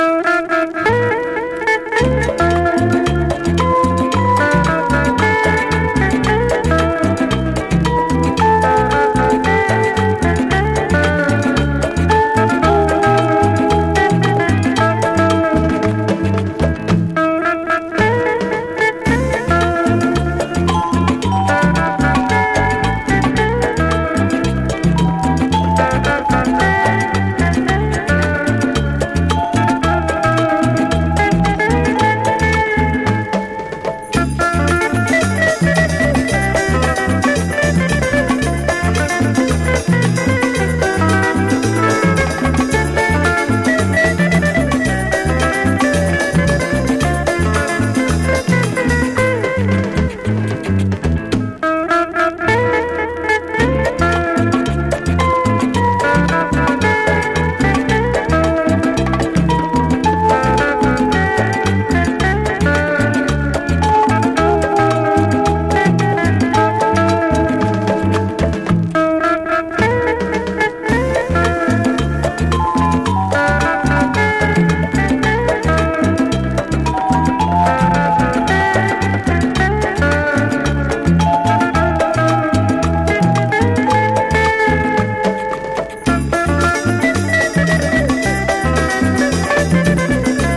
ペルー産サイケデリック・クンビア！